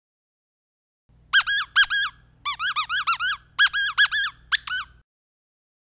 Squeaker Pillow Double-Voice - Medium (12 Pack)
Double-voice squeakers make a sound both when they are pressed and when they are released.